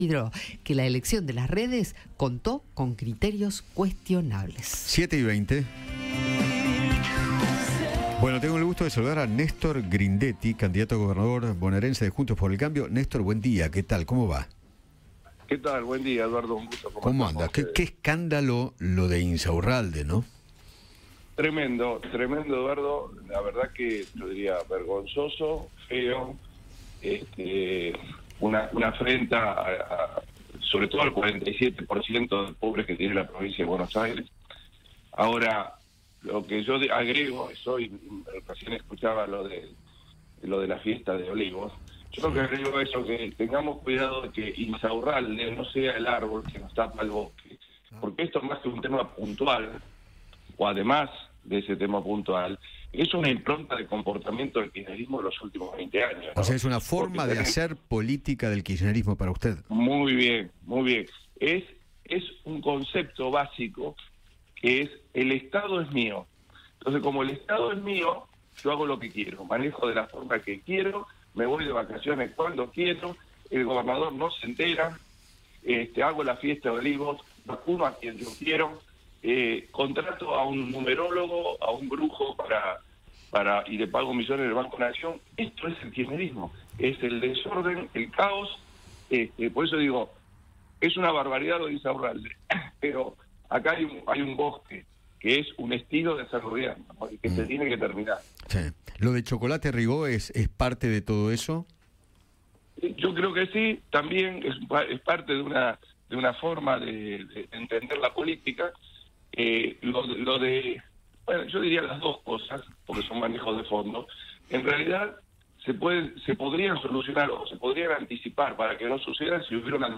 Néstor Grindetti, candidato a gobernador de la Provincia de Buenos Aires, conversó con Eduardo Feinmann sobre el escándalo de Martín Insaurralde. Al mismo tiempo, confirmó que anunciarán un principio de reforma fiscal.